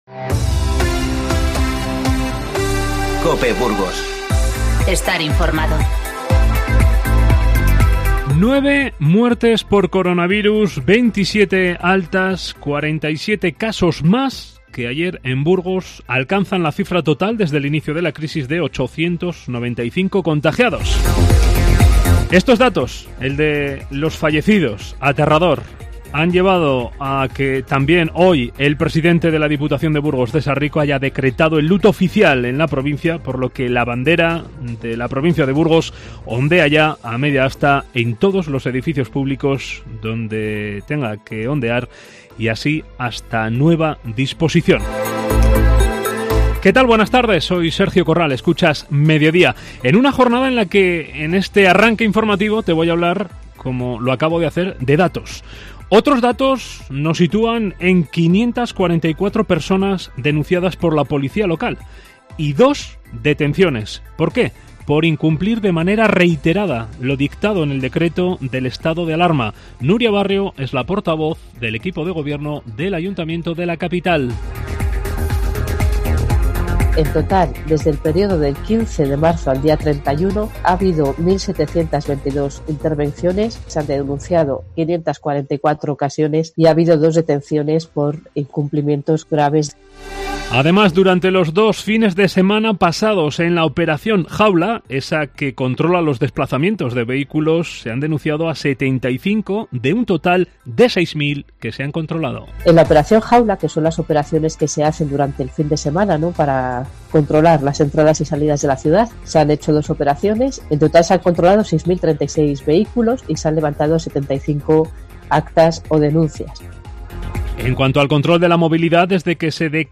Informativo 2-4-20